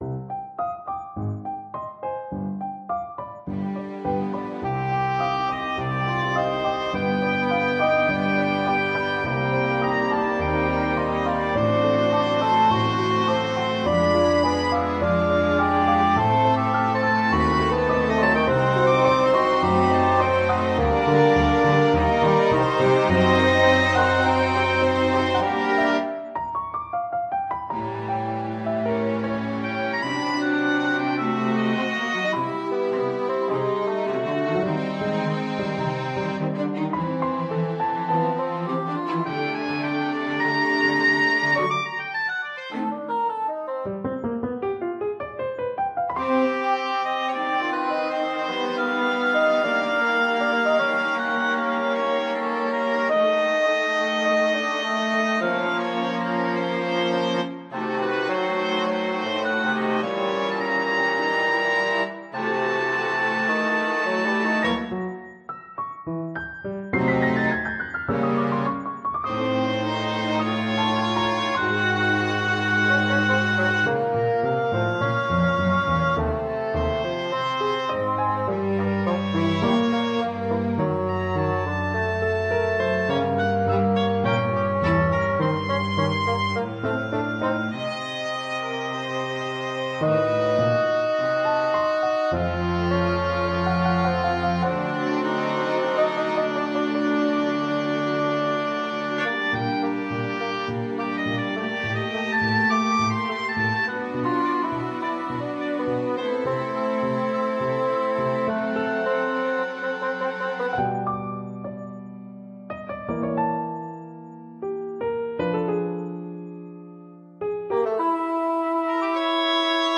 For Oboe, Bassoon, Piano & Strings
a series of playful and playable short pieces